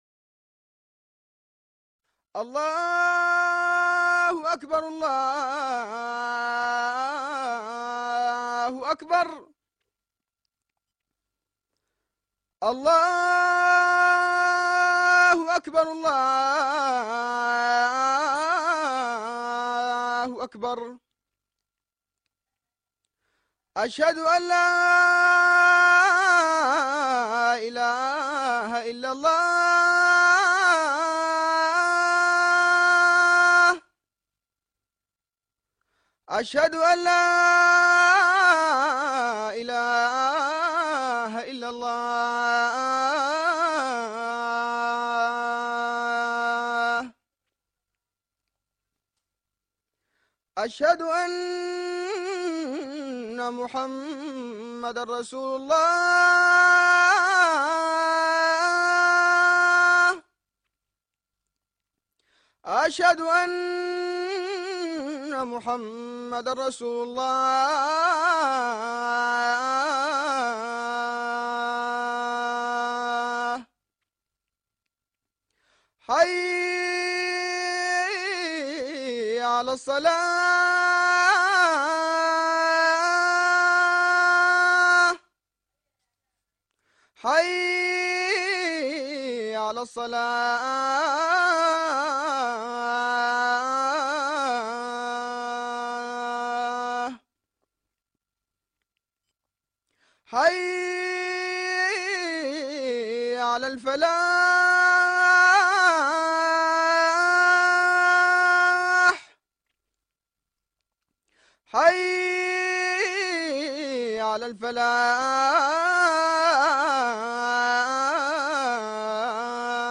الأذان بصوت مؤذن من السودان